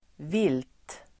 Uttal: [vil:t]